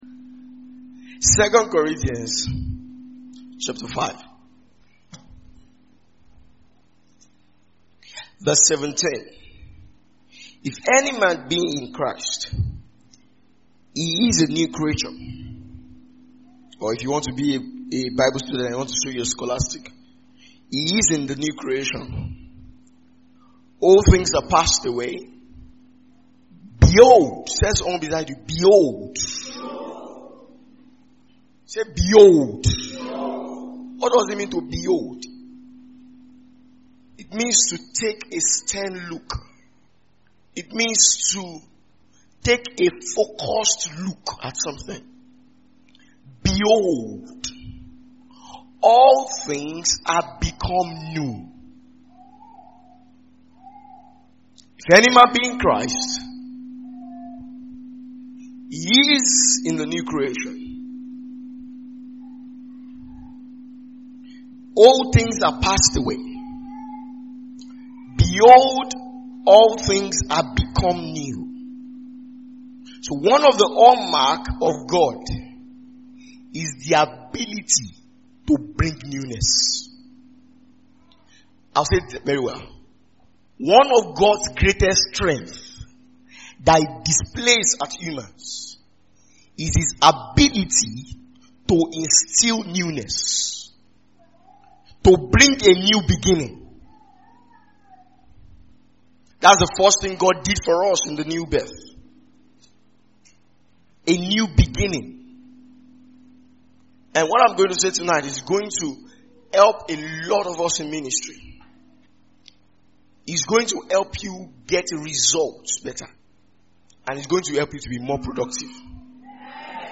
Behold a new beginning (Ministers’ Retreat 2023)
A teaching that reminds the believer that God specializes in creating new beginnings. It is one of the messages from our annual ministers’ retreat that will help the minister of the gospel let go of previous failures, disappointments and even successes, to seek fresh starts and greater exploits.